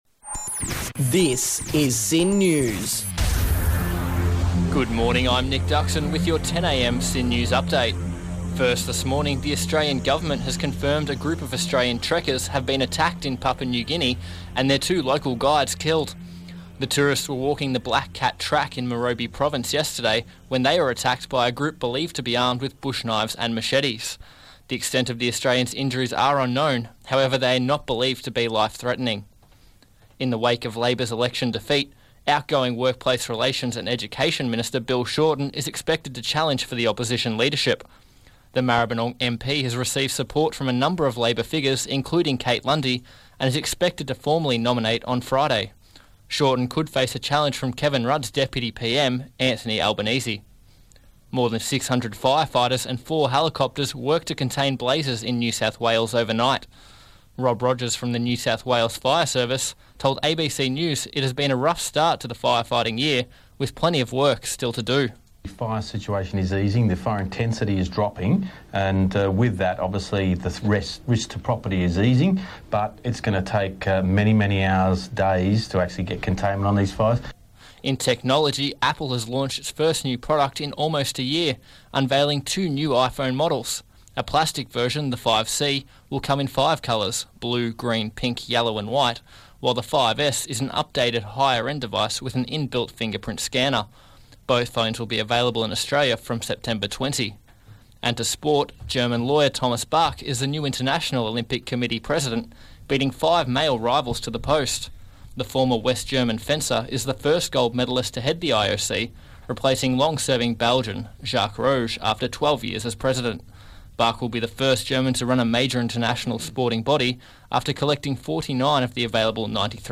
HEADLINES: